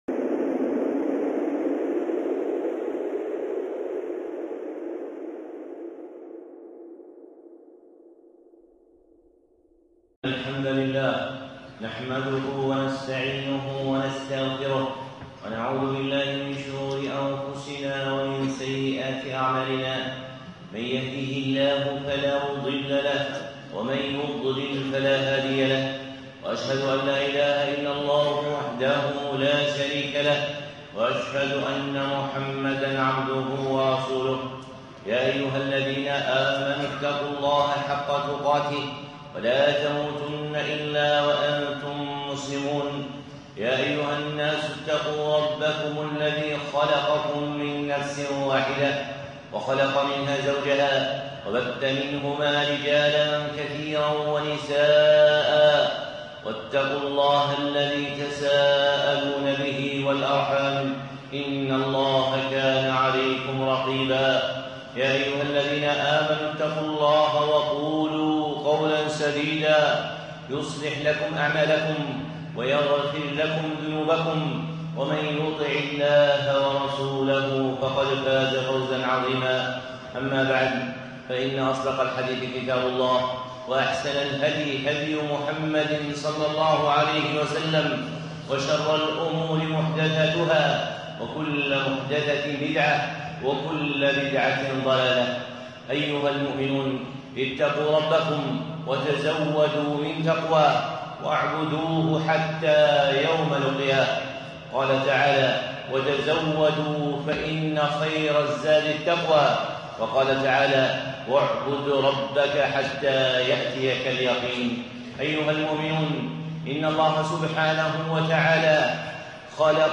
خطبة (من أحكام الرقية)